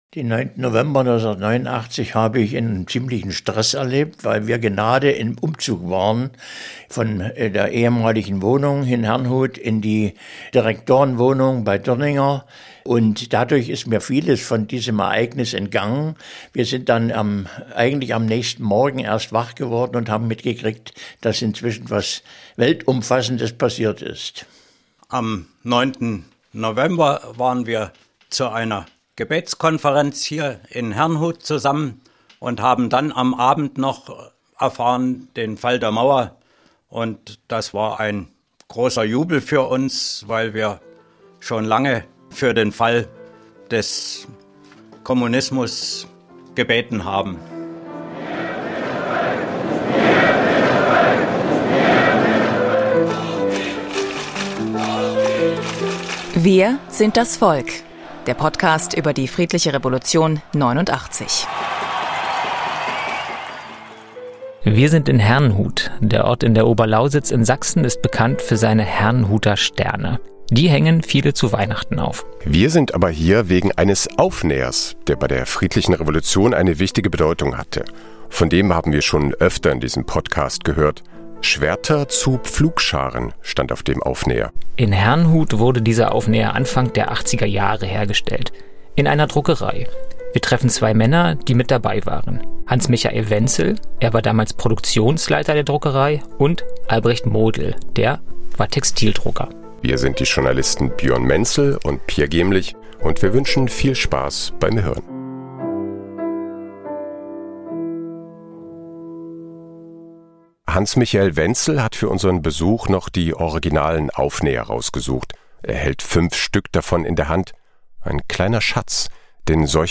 Beschreibung vor 1 Jahr Wir sind dieses Mal in Herrnhut.
Wir treffen zwei Männer, die mit dabei waren.